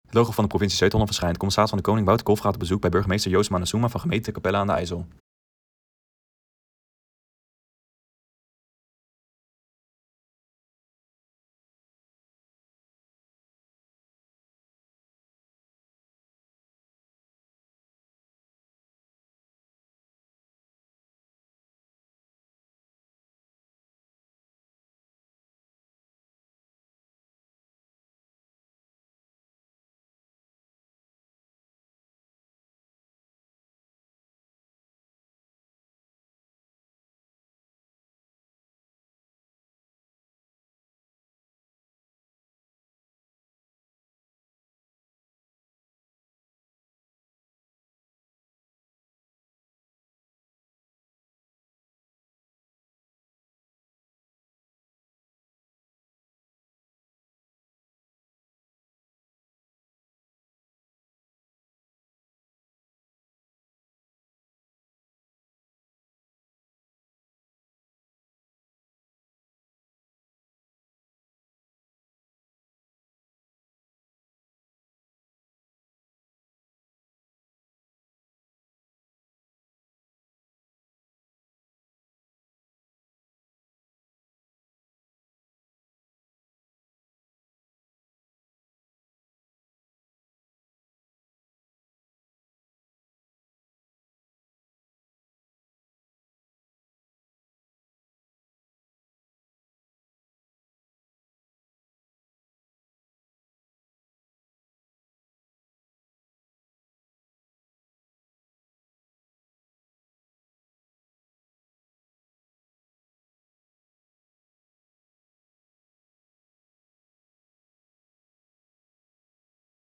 CdK in gesprek met burgemeester Capelle aan den IJssel